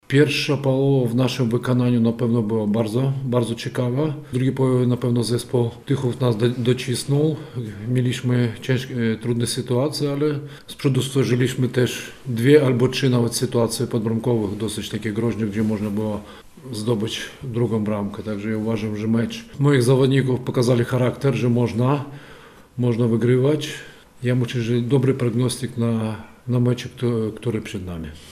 Na pomeczowej konferencji trener Górnika Jurij Szatałow mówił, że pierwsza połowa była bardzo ciekawa w wykonaniu jego zespołu.